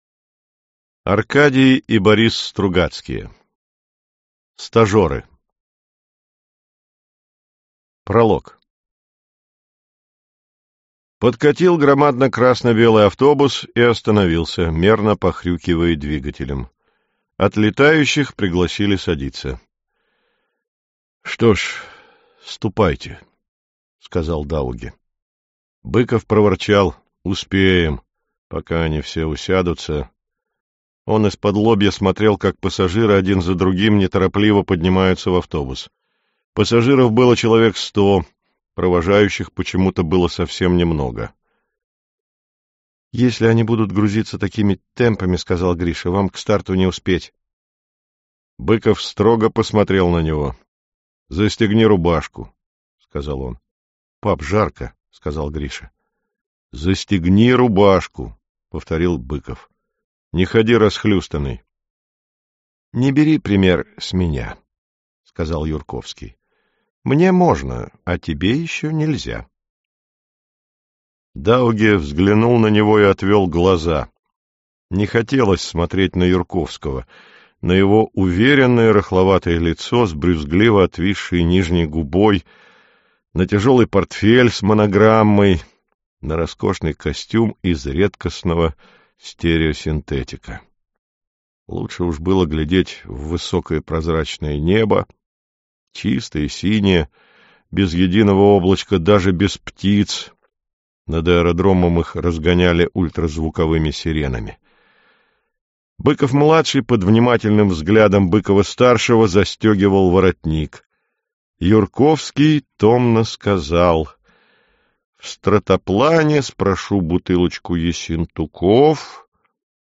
Аудиокнига Стажеры | Библиотека аудиокниг